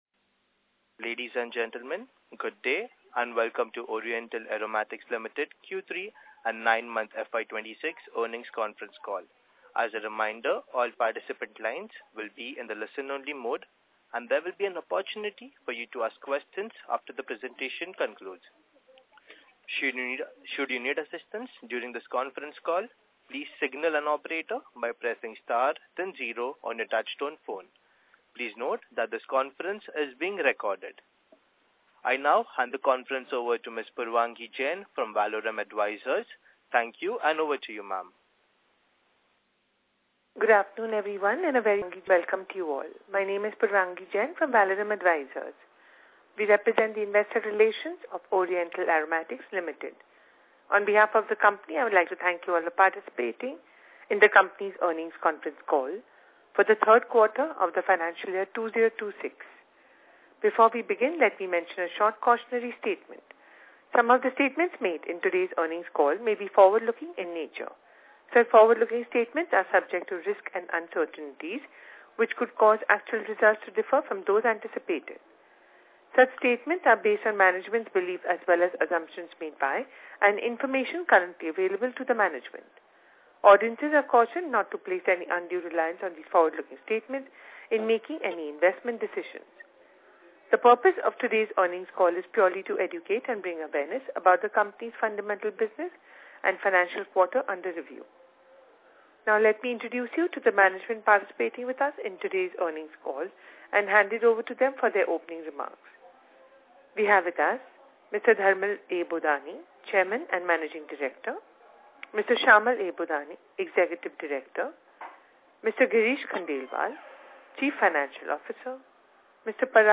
Concall-OrientalAromaticsLtd-Q325-26.mp3